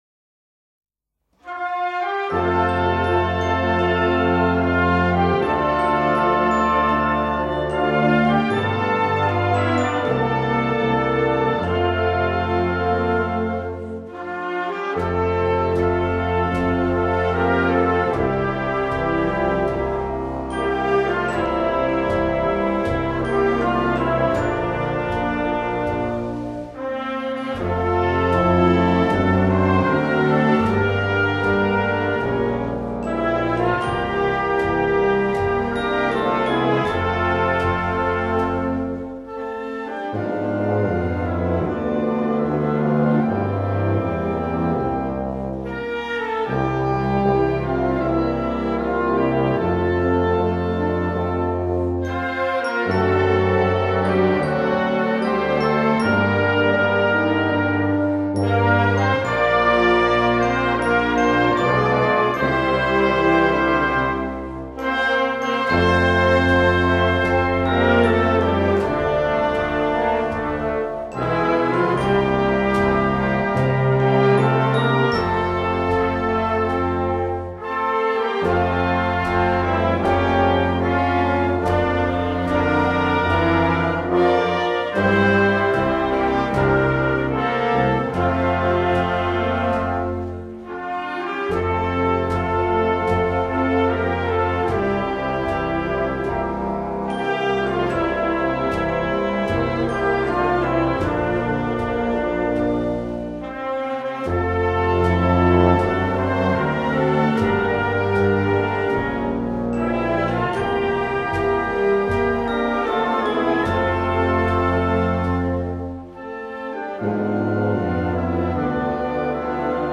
演奏／竹台高等学校吹奏楽部
校歌（歌あり）　　 　 　校歌（歌なし）